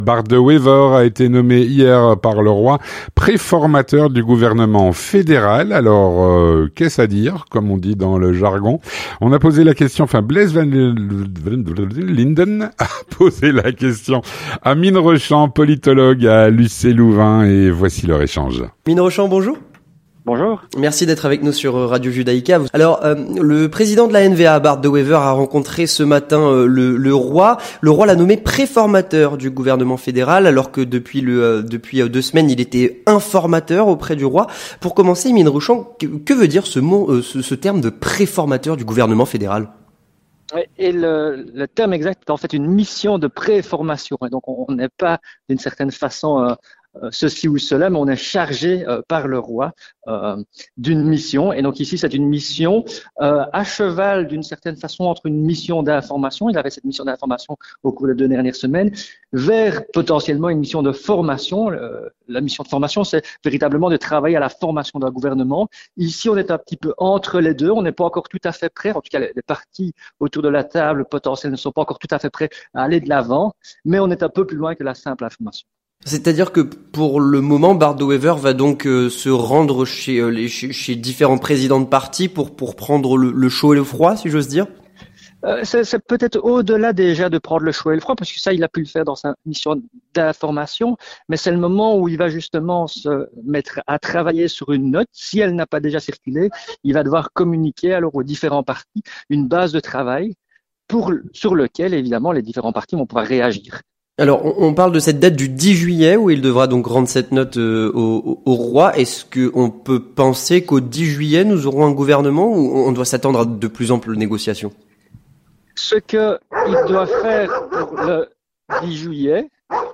L'entretien du 18H - Bart De Wever nommé préformateur du gouvernement fédéral.